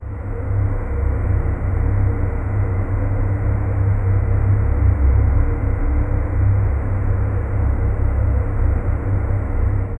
Alive hallway drone ambience
alive-hallway-drone-ambie-aeepvwa5.wav